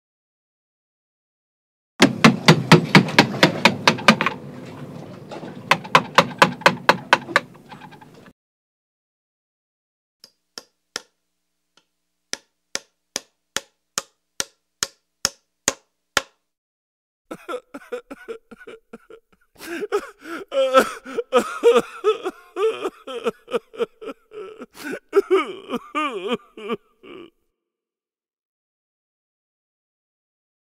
دانلود آهنگ چکش و میخ از افکت صوتی اشیاء
دانلود صدای چکش و میخ از ساعد نیوز با لینک مستقیم و کیفیت بالا
جلوه های صوتی